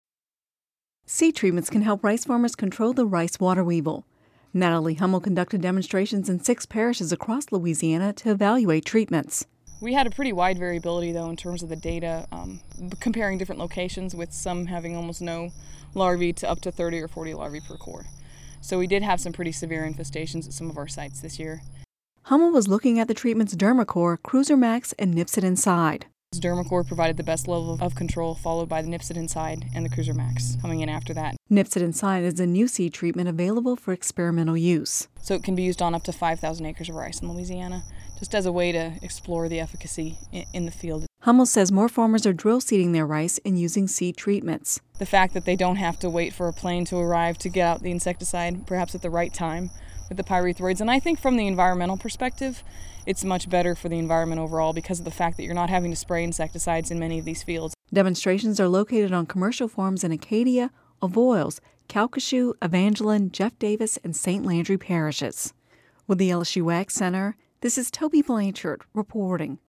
(Radio News 07/19/11) Seed treatments can help rice farmers control the rice water weevil.